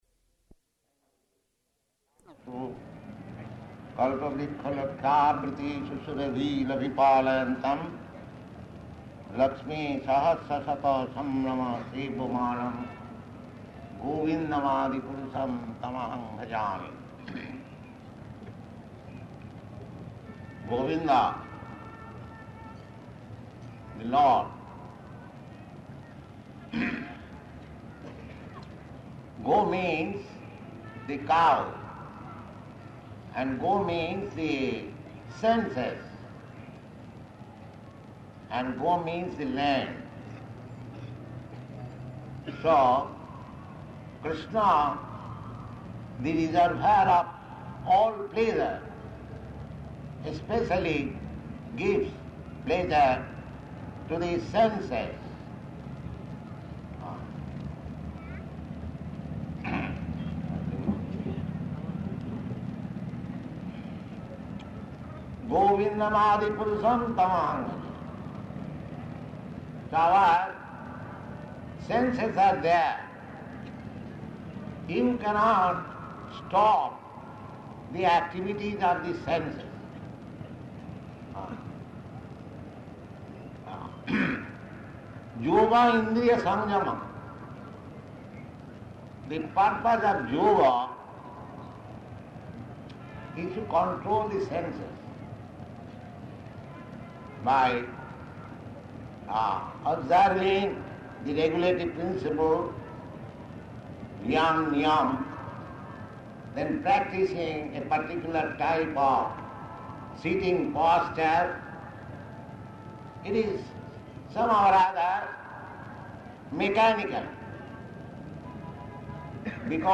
Paṇḍāl Lecture
Type: Lectures and Addresses
Location: Delhi